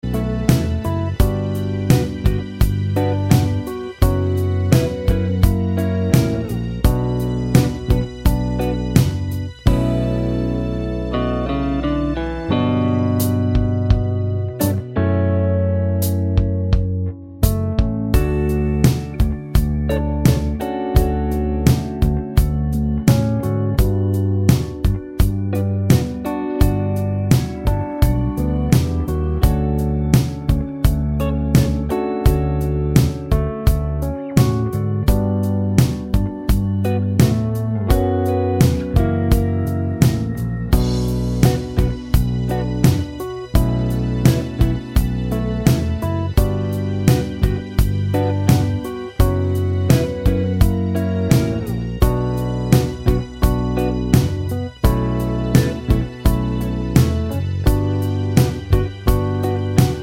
no Backing Vocals Soft Rock 4:09 Buy £1.50